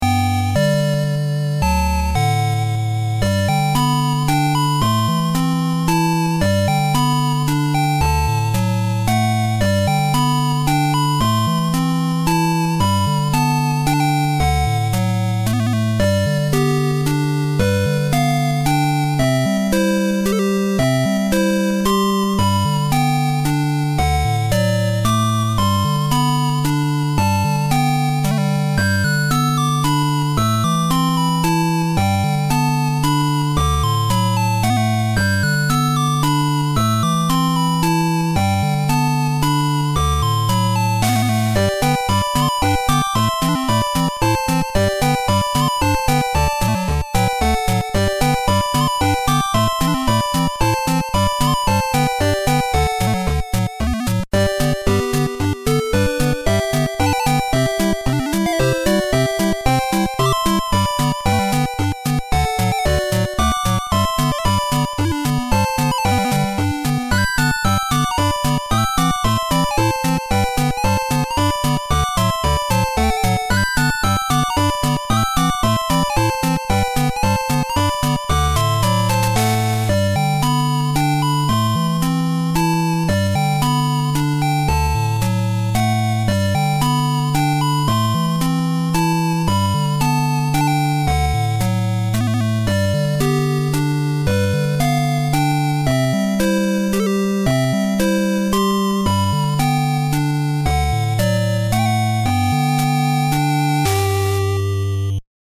8-bit chiptune song called "Festival Day."
I like when the tempo goes up as if you stumbled upon a festival in the city 😊